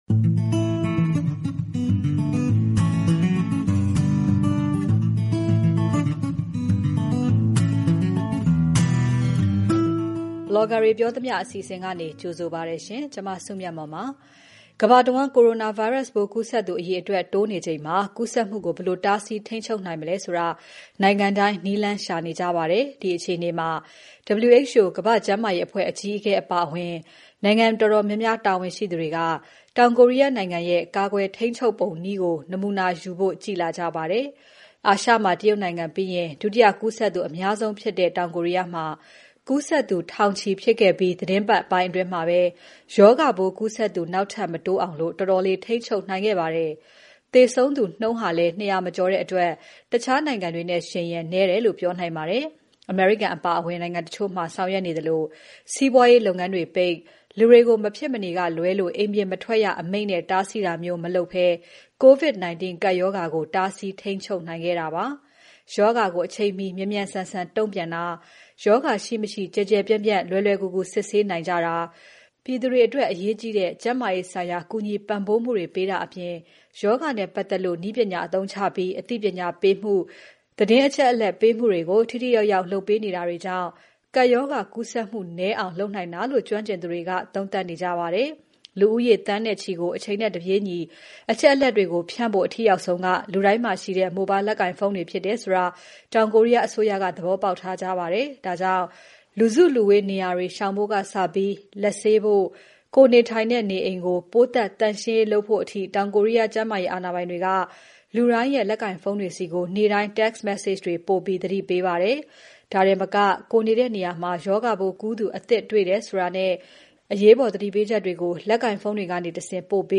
မေးမြန်းပြီး ဘလော်ဂါတွေပြောသမ ျှ အစီအစဉ်မှာ တင်ပြထားပါတယ်။